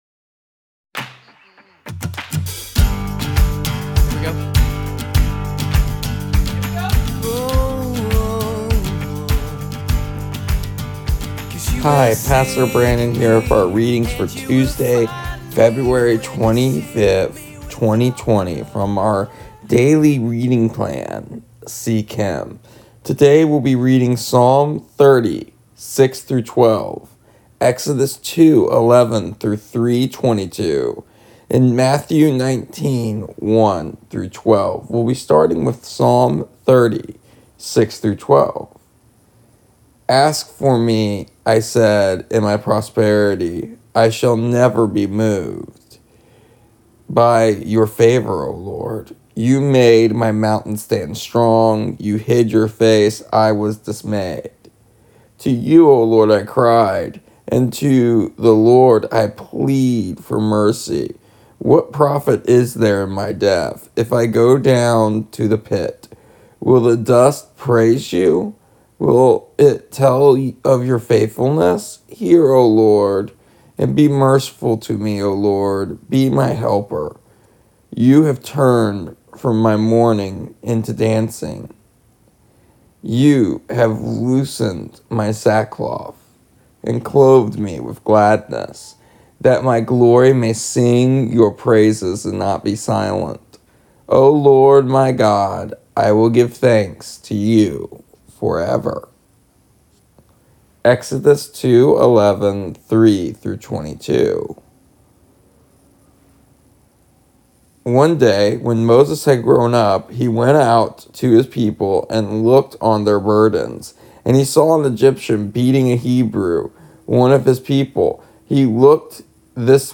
Here is the audio version of our daily readings. Today we are holding off our daily devotional for tomorrow as we continue to seek Him through the calling of Moses found in Exodus.